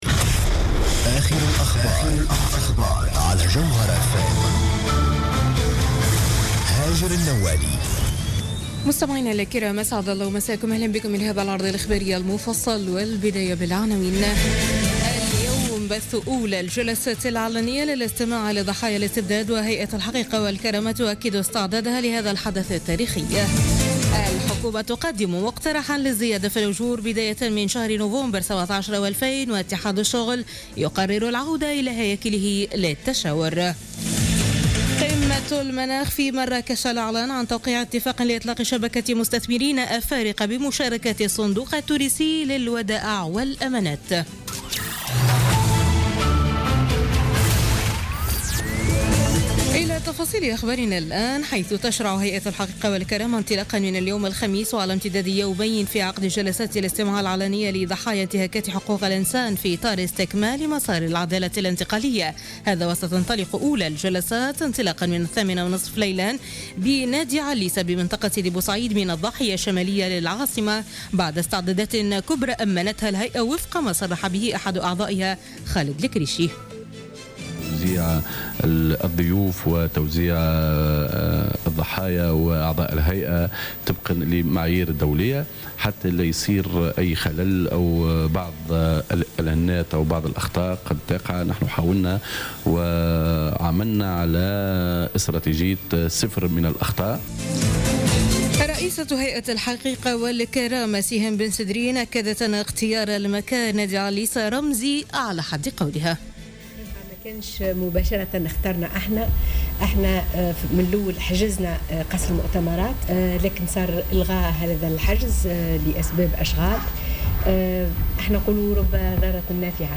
نشرة أخبار منتصف الليل ليوم الخميس 17 نوفمبر 2016